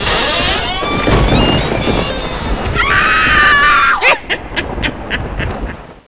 crkscrelaugh.wav